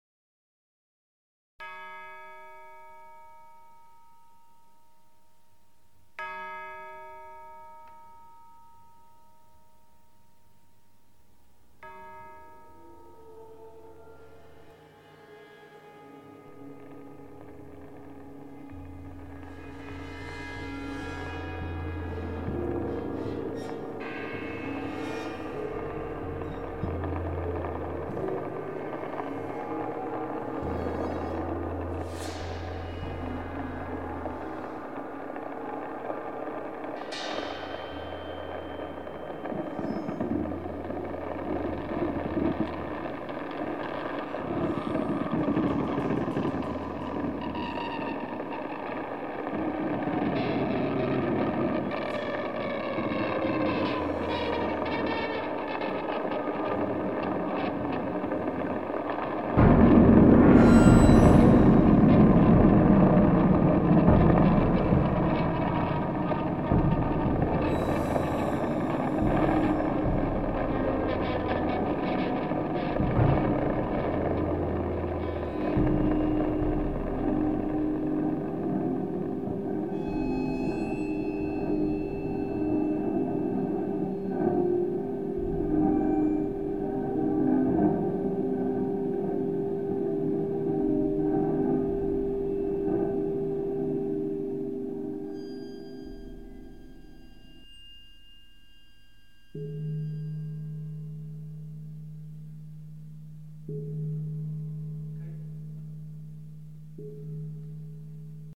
The sound component of the wallpaper refers directly to this, with extracts from Gavin Bryars' musical piece The Sinking of the Titanic.